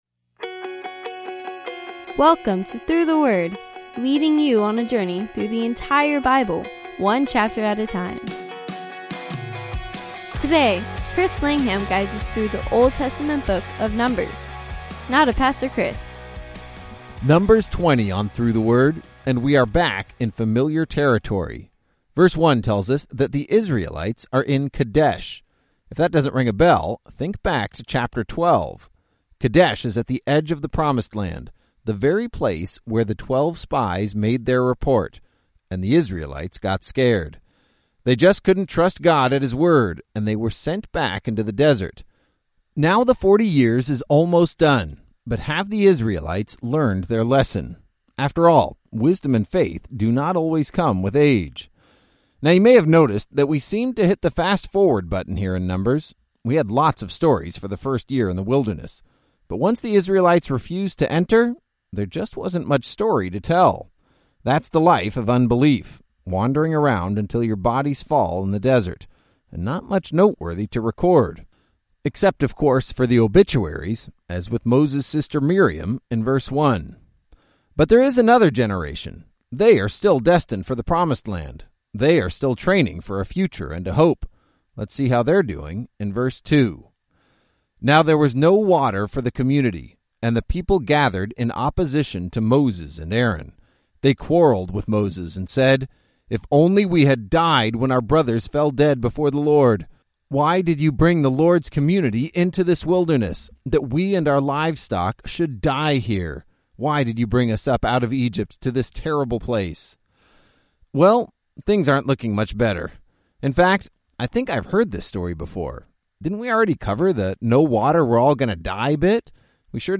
19 Journeys is a daily audio guide to the entire Bible, one chapter at a time. Each journey opens an epic adventure through several Bible books as your favorite teachers explain each chapter.